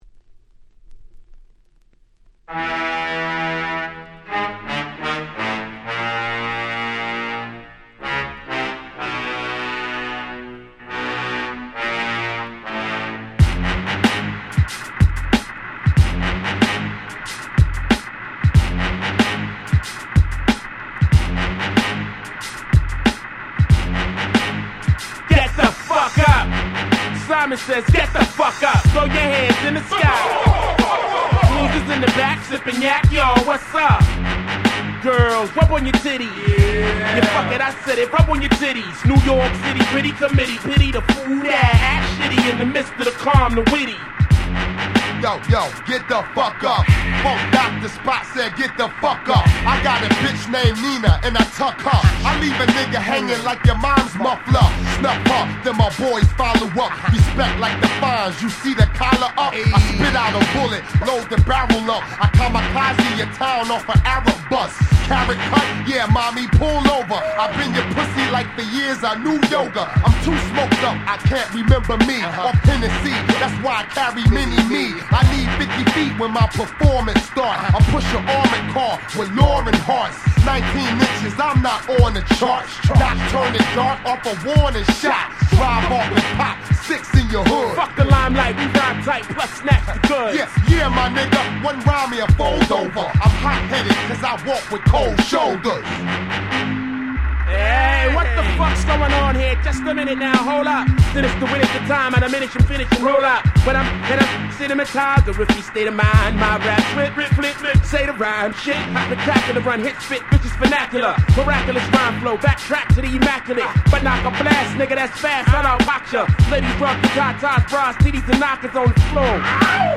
99' Super Hit Hip Hop !!